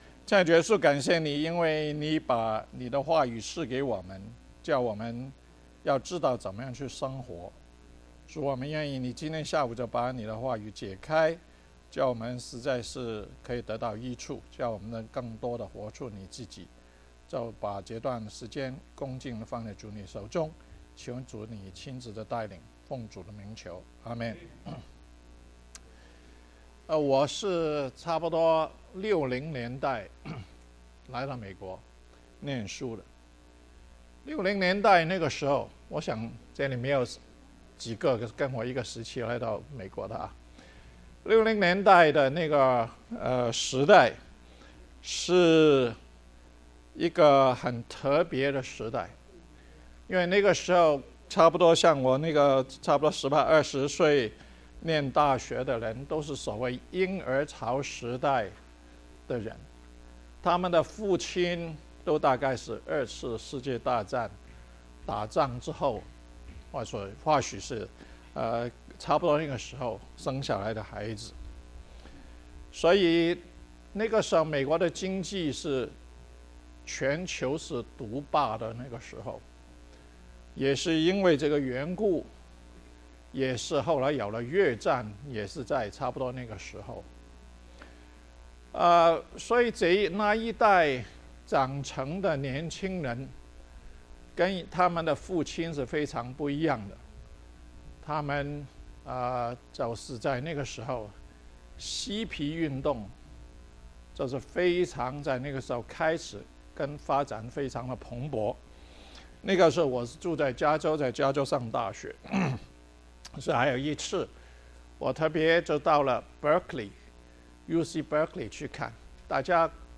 活在神的設計裡： 細讀以弗所書 – 第十二講